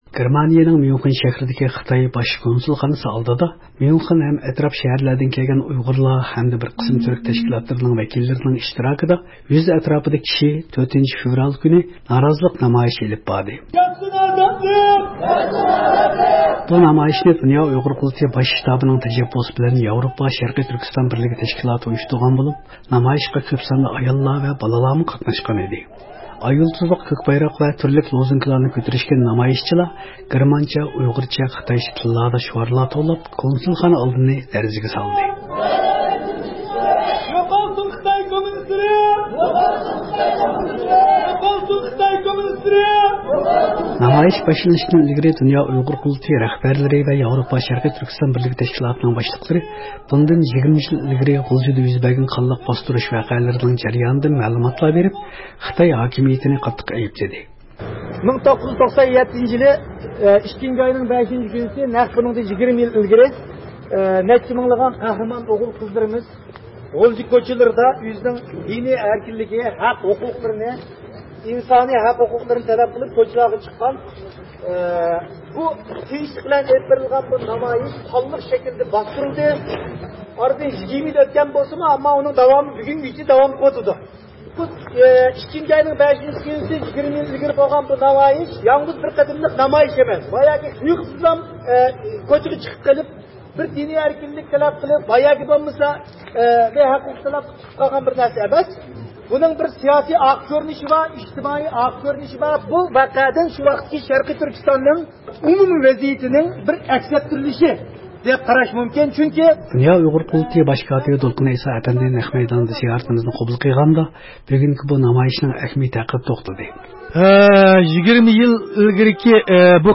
1997-يىلى 5-فېۋرال كۈنى غۇلجىدا يۈز بەرگەن قانلىق باستۇرۇش ۋەقەسىنىڭ 20 يىللىقنى خاتىرىلەش يۈزىسىدىن گېرمانىيەنىڭ ميۇنخېن شەھىرىدىكى خىتاي باش كونسۇلخانىسى ئالدىدا ميۇنخېن ھەم ئەتراپ شەھەرلەردىن كەلگەن ئۇيغۇرلار ھەمدە بىر قىسىم تۈرك تەشكىلاتلىرىنىڭ ۋەكىللىرىنىڭ ئىشتىراكىدا 100 ئەتراپىدا كىشى 4-فېۋرال كۈنى نارازىلىق نامايىشى ئېلىپ باردى.
ئاي يۇلتۇزلۇق كۆك بايراق ۋە تۈرلۈك لوزۇنكىلارنى كۆتۈرۈشكەن نامايىشچىلار گېرمانچە، ئۇيغۇرچە، خىتايچە تىللاردا شۇئارلار توۋلاپ، كونسۇلخانا ئالدىنى لەرزىگە سالدى.
د ئۇ ق باش كاتىپى دولقۇن ئەيسا ئەپەندى نەق مەيداندا زىيارىتىمىزنى قوبۇل قىلغاندا بۈگۈنكى بۇ نامايىشنىڭ ئەھمىيىتى ھەققىدە توختالدى.